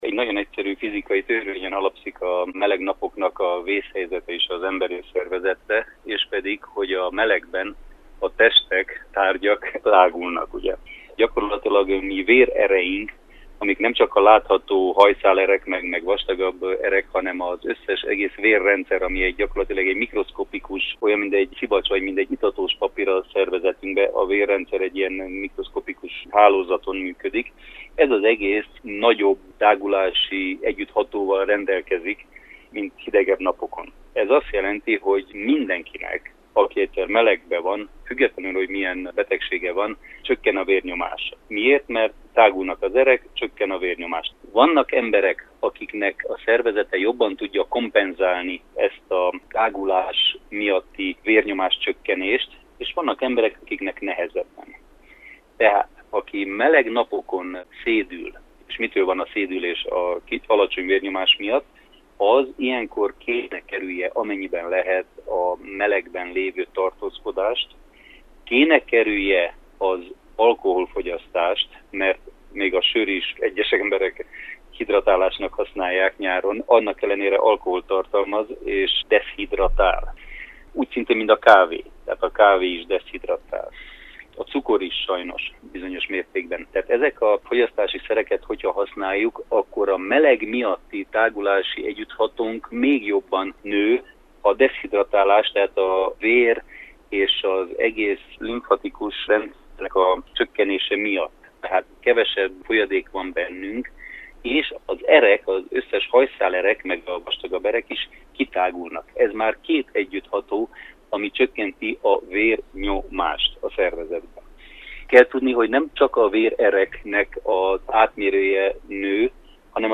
Riporter: